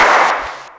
TM88 BoomFX.wav